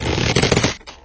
slingshot_streched.wav